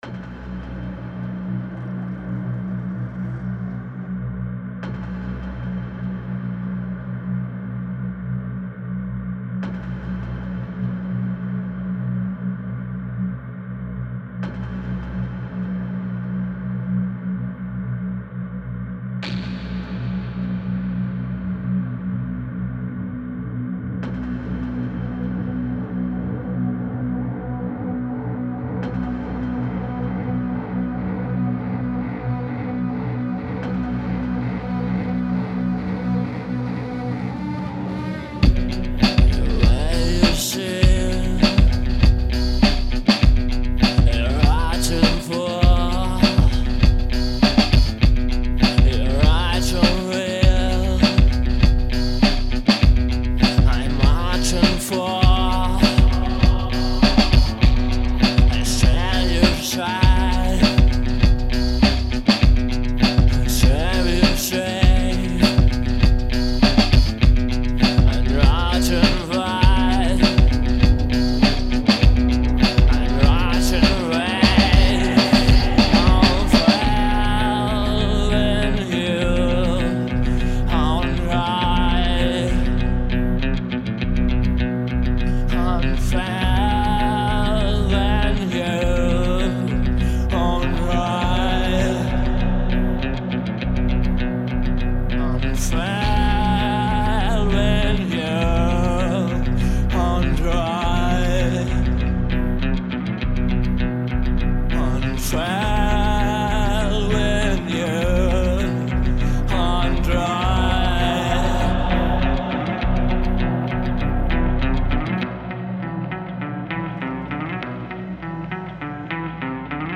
Нужен вокалист (немецкий или английский) совместная работа Альтернативная музыка